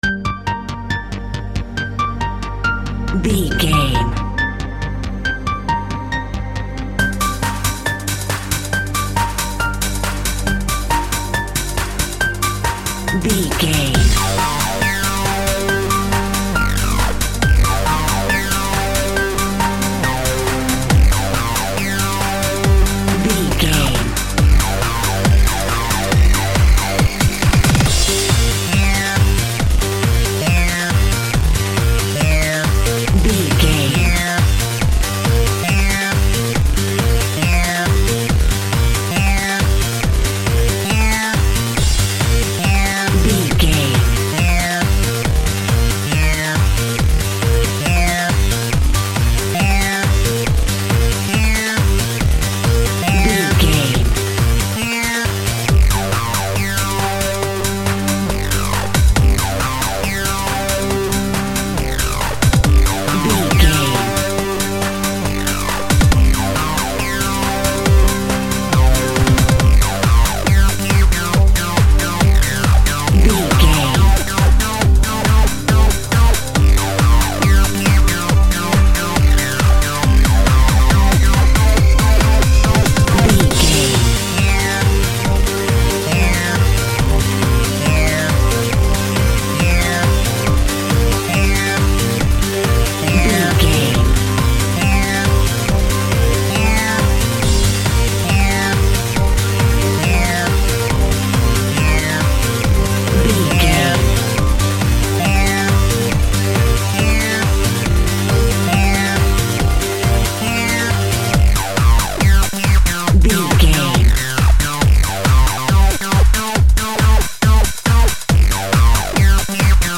Club Bass Dance Music.
Aeolian/Minor
Fast
groovy
smooth
futuristic
frantic
drum machine
synthesiser
electric piano
electro dance
electronic
techno
synth bass
upbeat